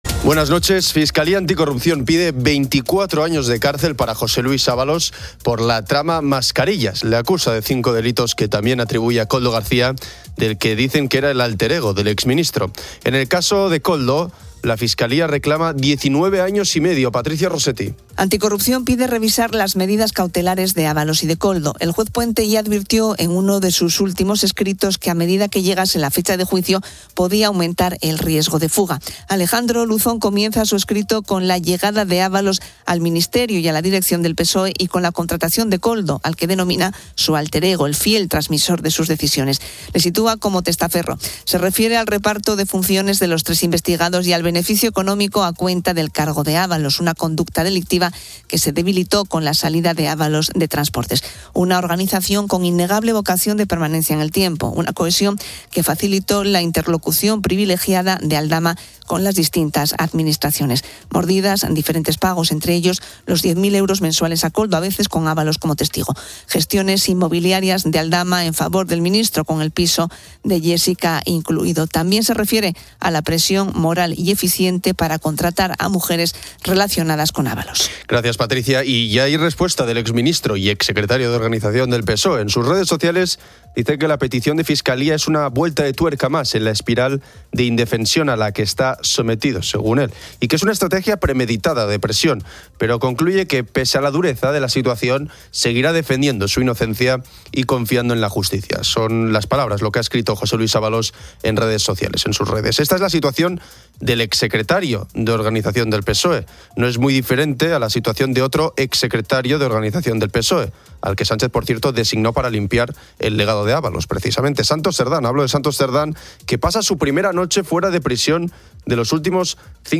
En el ámbito cultural, se entrevista al director David Trueba sobre su nueva película, "Siempre es invierno", una historia sobre el desamor y la búsqueda de identidad que desafía las convenciones cinematográficas y sociales.